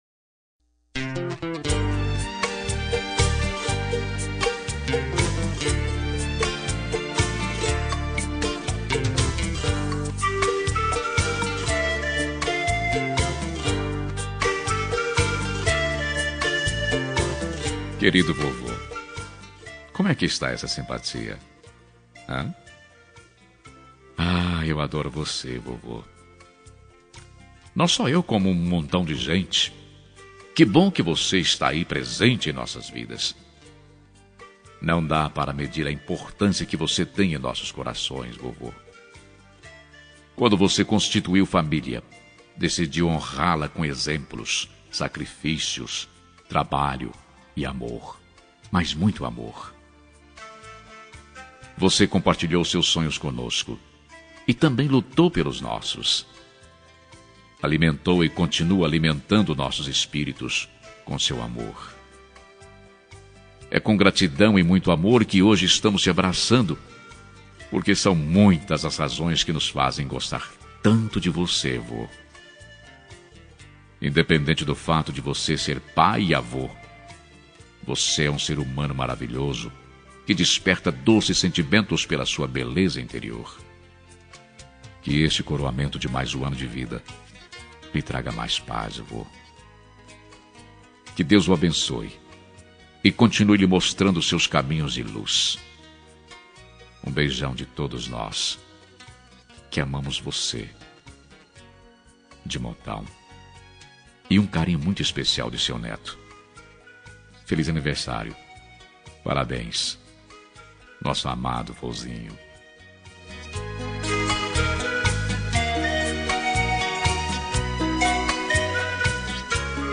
Aniversário de Avô – Voz Masculina – Cód: 2105